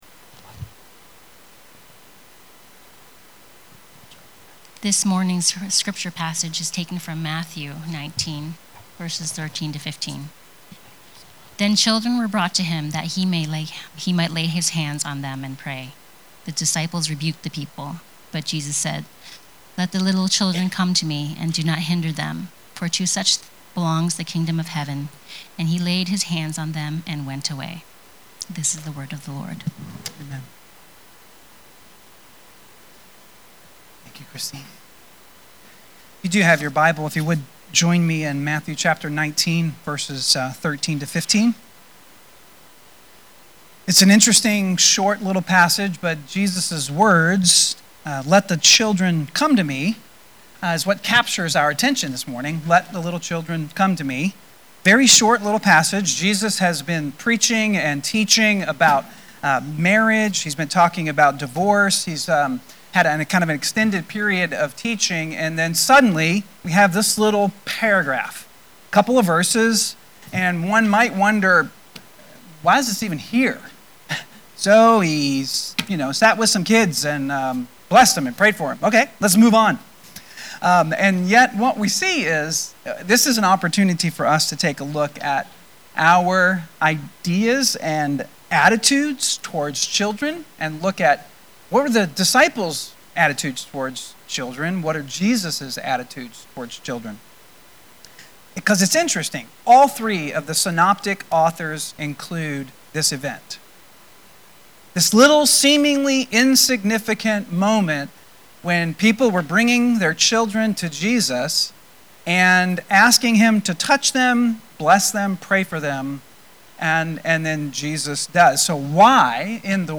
Sermons | Hope Christian Church